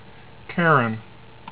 "KAIR en" ) is Pluto's only known satellite: